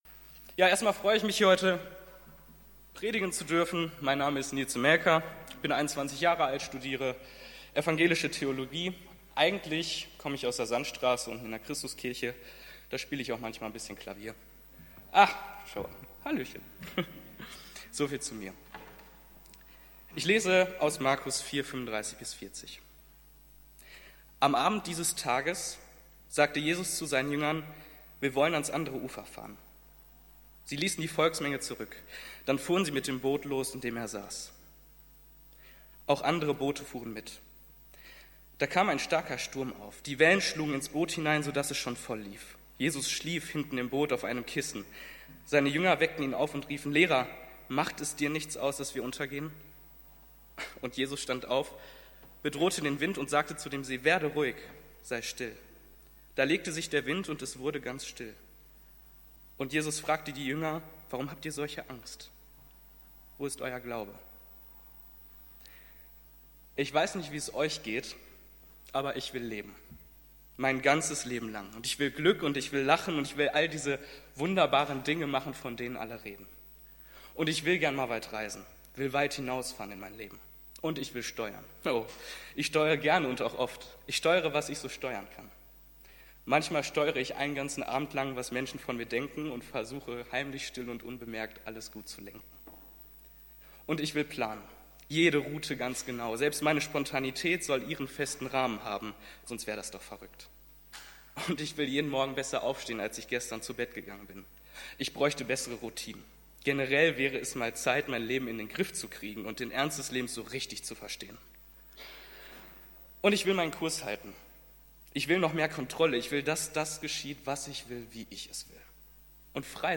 Gottesdienst mit Abendmahl
Juli 28, 2019 | Predigten | 0 Kommentare